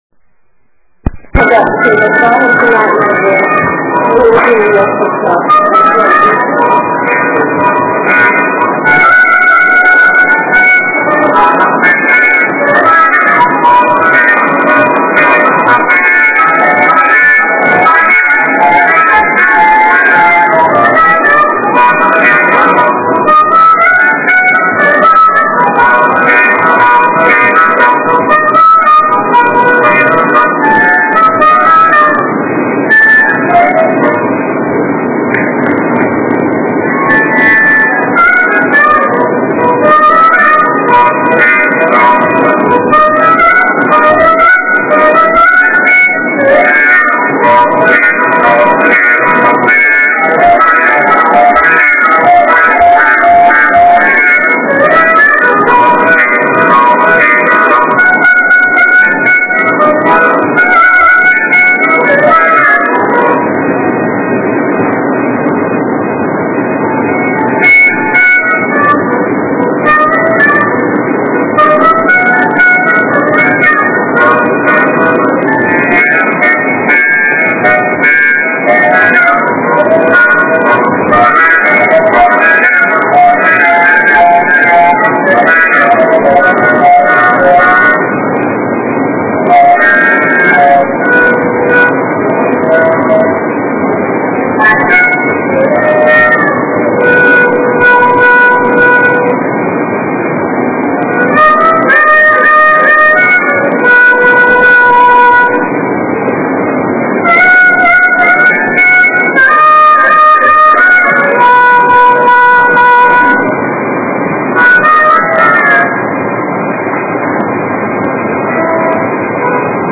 Harmonica
Street Folk
harmonica in downtown Berkeley BART station